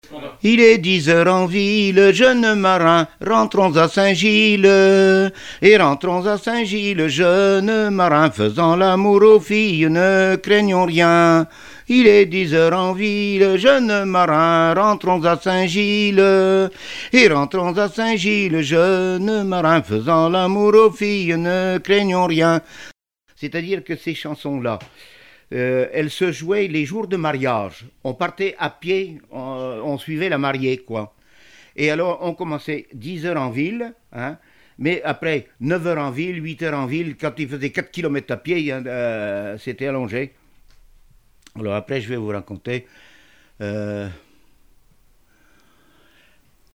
Mémoires et Patrimoines vivants - RaddO est une base de données d'archives iconographiques et sonores.
gestuel : à marcher
circonstance : fiançaille, noce
Genre énumérative
Répertoire de chansons populaires et traditionnelles
Pièce musicale inédite